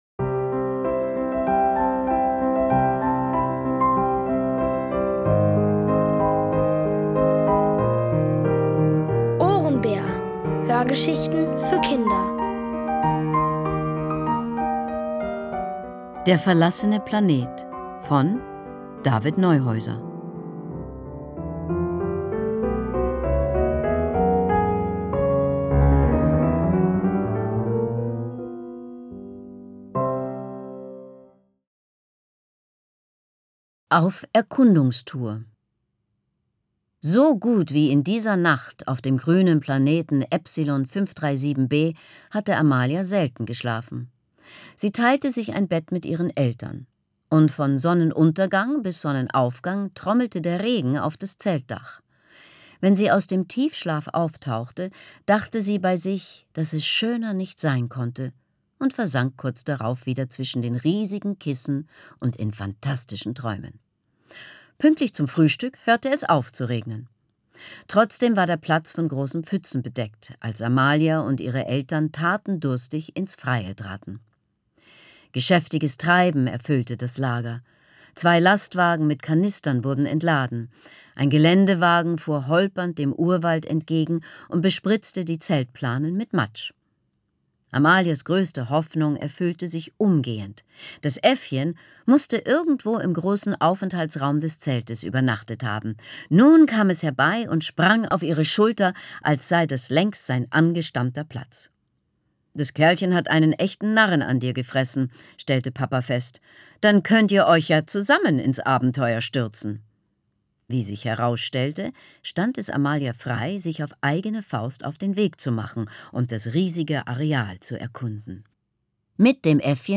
Von Autoren extra für die Reihe geschrieben und von bekannten Schauspielern gelesen.
Es liest: Leslie Malton.